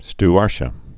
(st-ärshə, -shē-ə, -tē-ə, sty-)